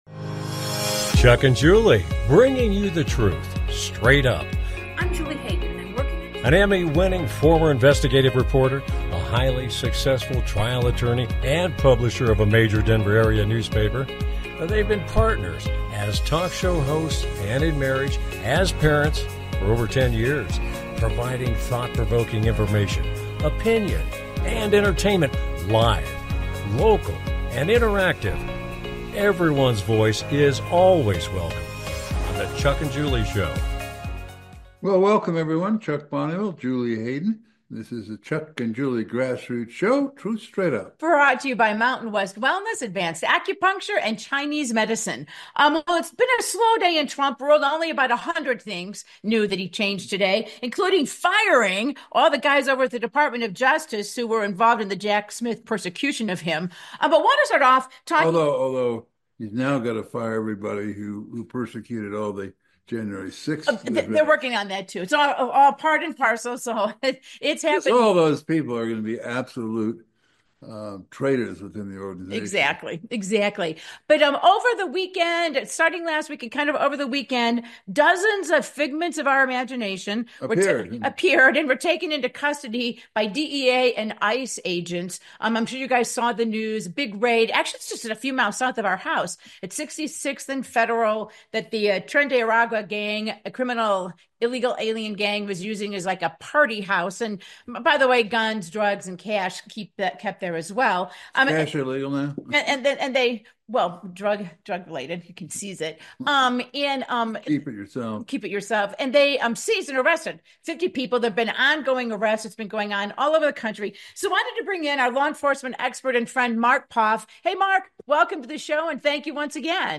With Guest, Former El Paso County Sheriff and Law enforcement expert